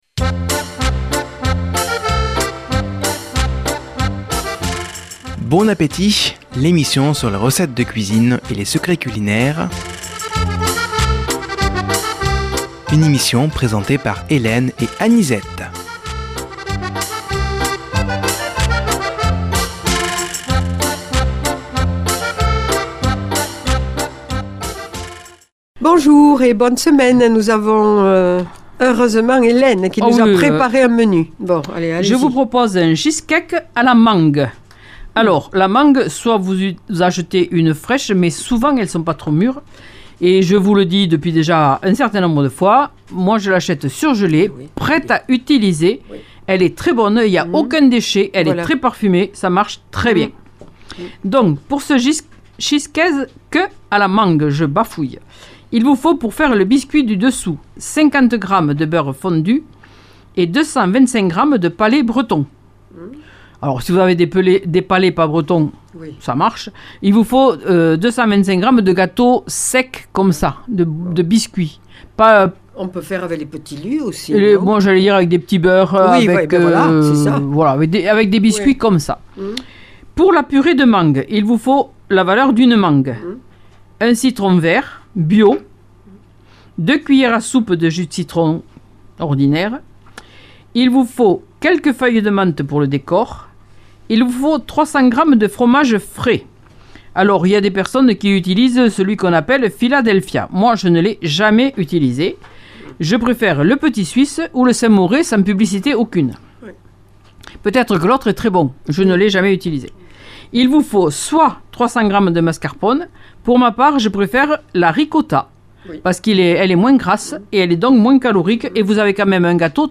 Présentatrices